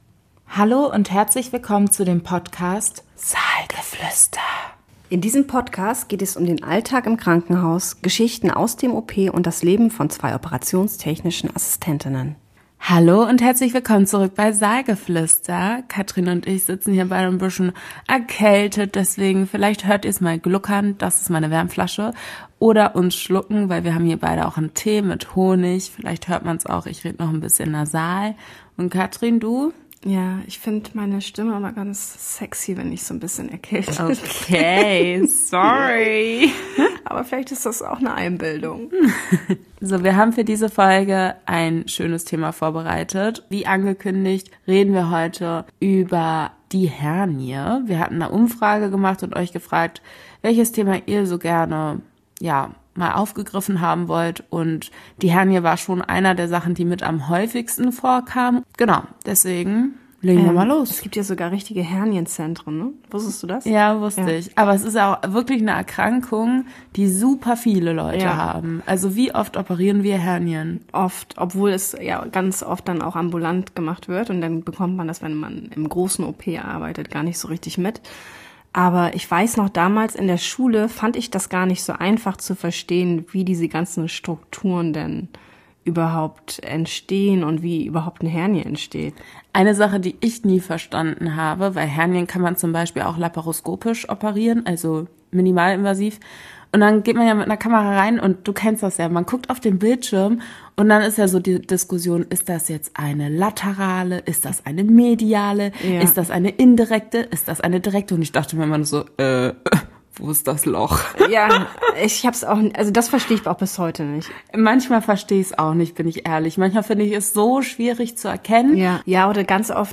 In dieser Folge von Saalgeflüster sprechen wir locker über das Thema Hernienoperationen und geben Einblicke in verschiedene Verfahren, Abläufe und wichtige Aspekte rund um den Eingriff.
Natürlich darf auch ein wenig Privattalk nicht fehlen, sodass wieder eine entspannte Mischung aus Fachlichem und Persönlichem entstanden ist.